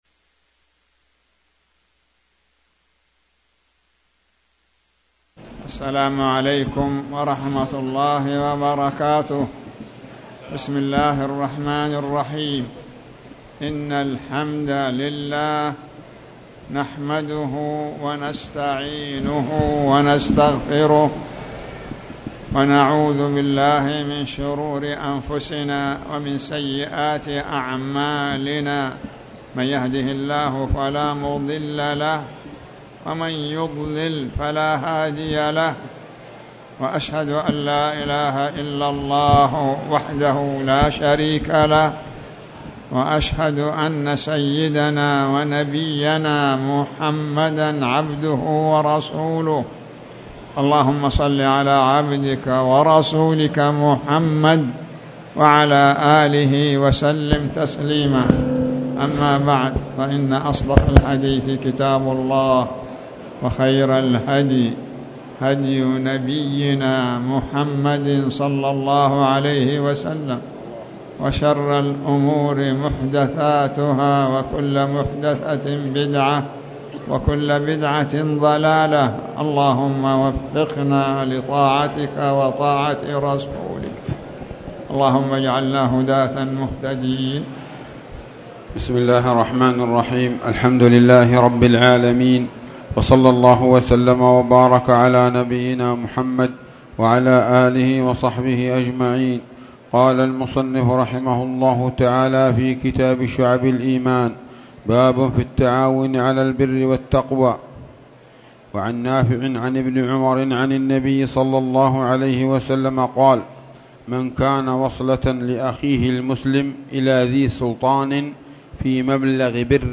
تاريخ النشر ٩ ذو القعدة ١٤٣٨ هـ المكان: المسجد الحرام الشيخ